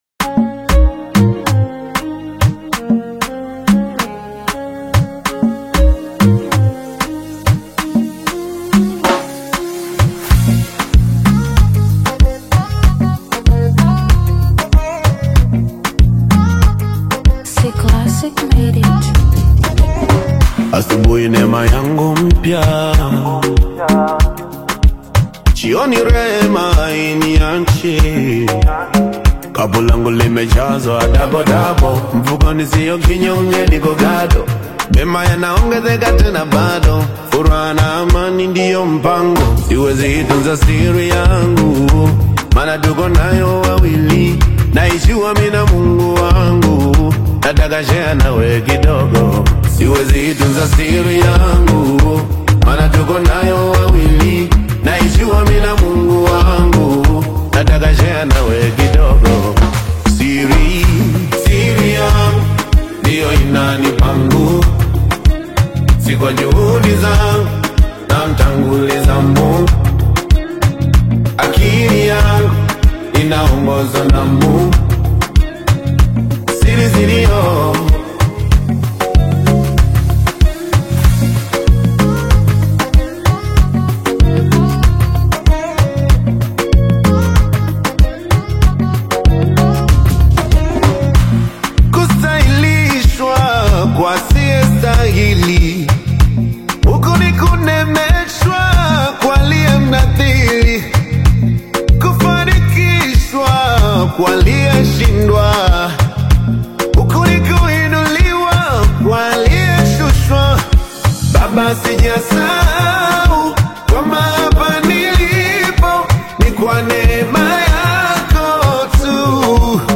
AudioGospel